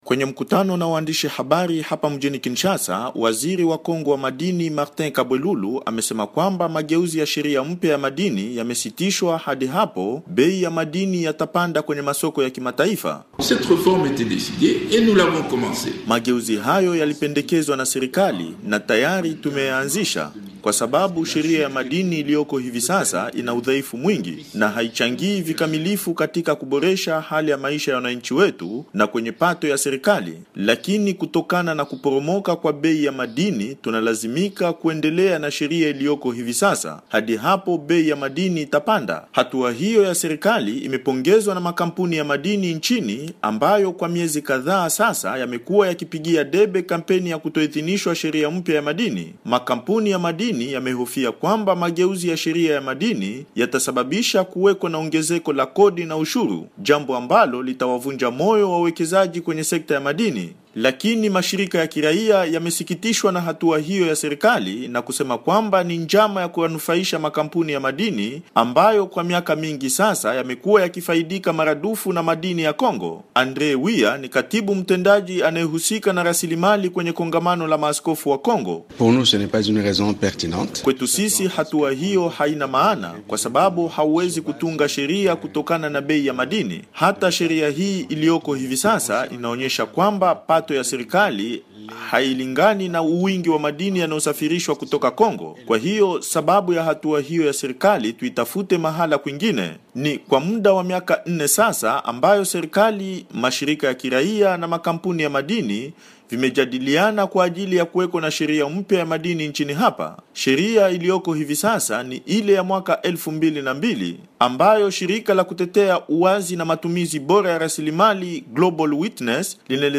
Kinshasa, DRC